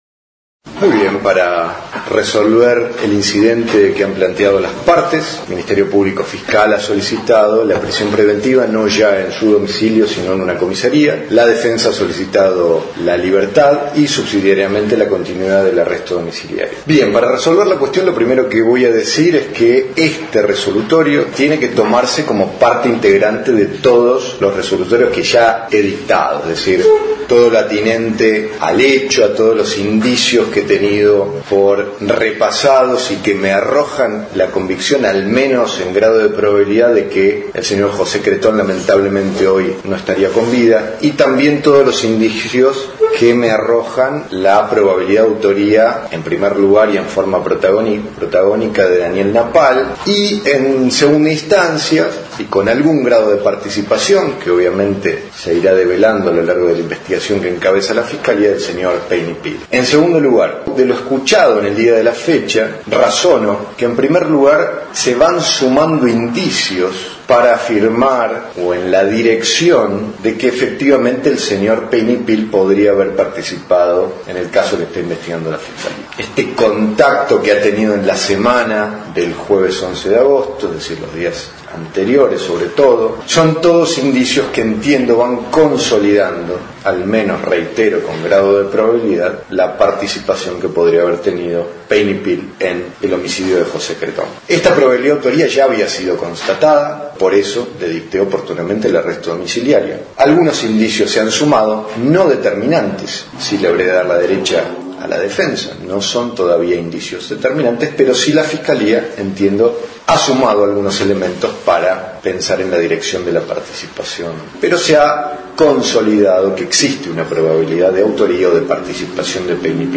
Esta fue la resolución del Juez Penal Martín O´Connor.